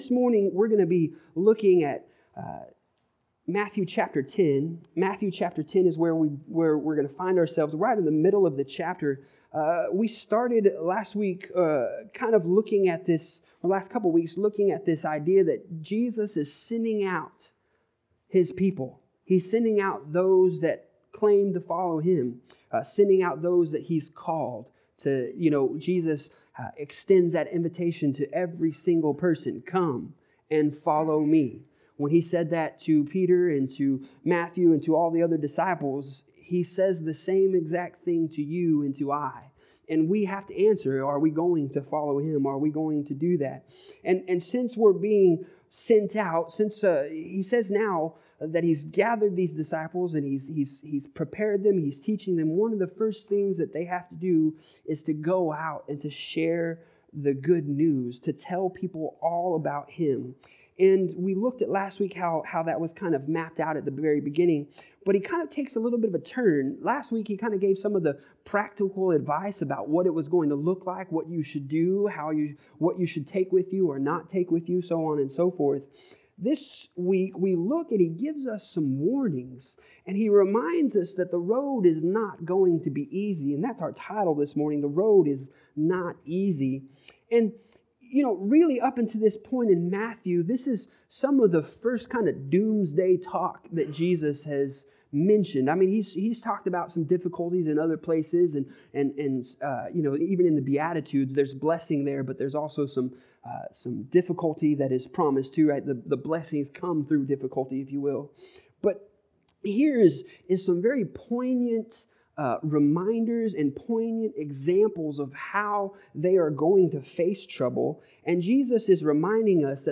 Passage: Matthew 10:16-24 Service Type: Sunday Morning